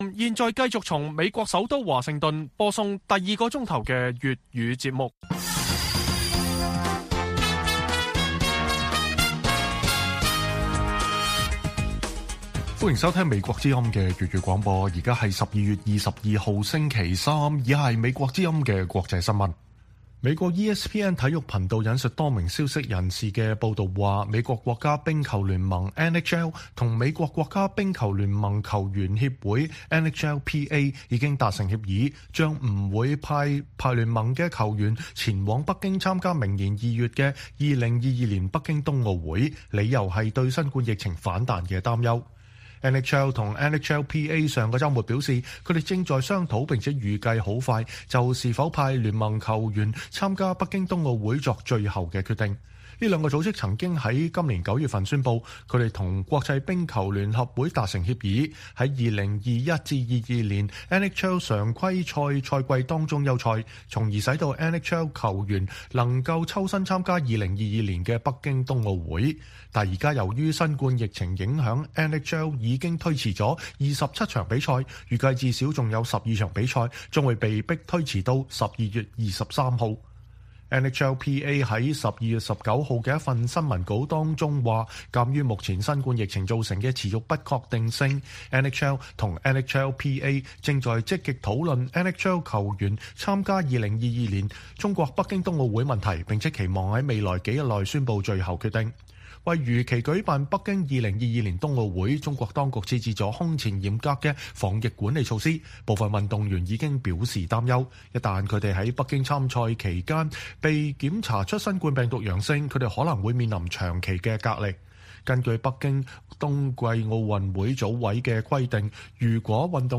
粵語新聞 晚上10-11點:新冠疫情反彈NHL球員將缺席北京冬奧